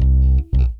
Bass Rnb 1.wav